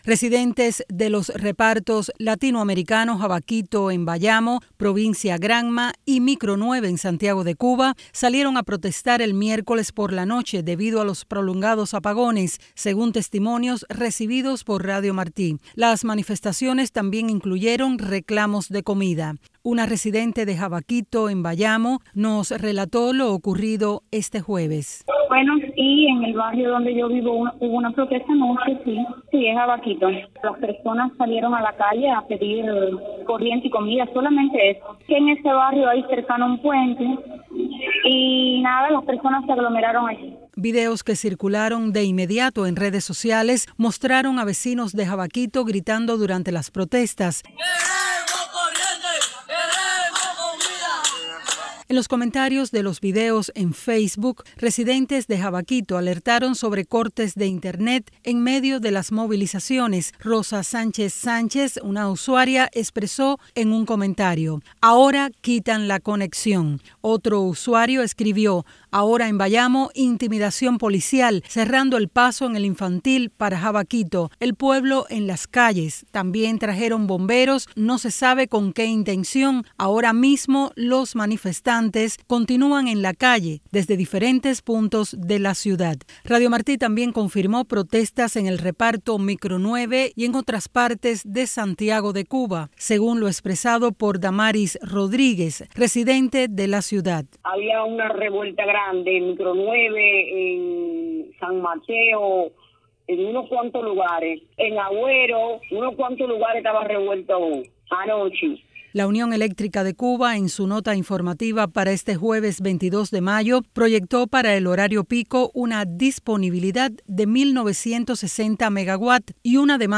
Testimonios: Protestas por los apagones en Cuba
Vecinos de Santiago de Cuba y Bayamo confirmaron a Martí Noticias las protestas de este miércoles en ambas ciudades.